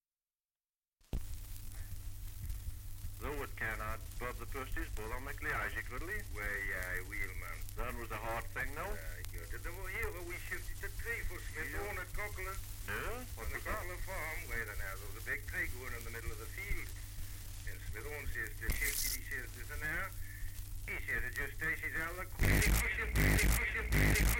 Dialect recording in Wall, Northumberland
78 r.p.m., cellulose nitrate on aluminium